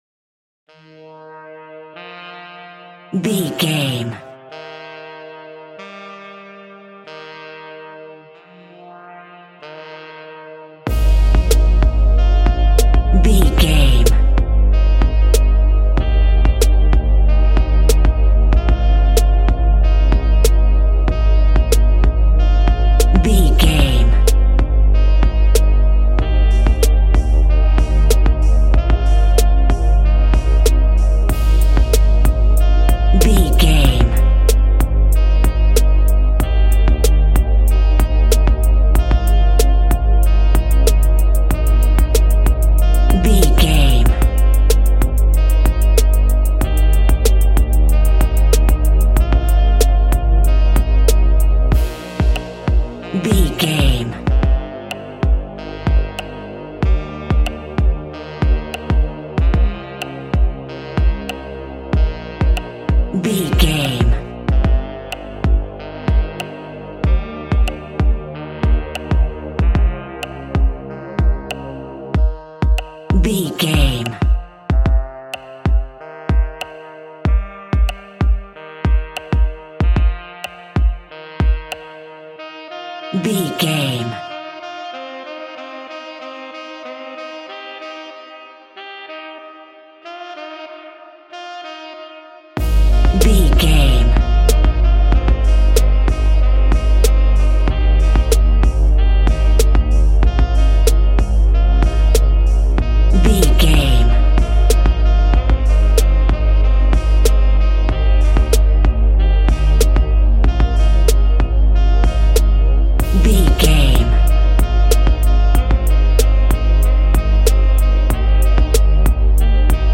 Aeolian/Minor
chilled
laid back
groove
hip hop drums
hip hop synths
piano
hip hop pads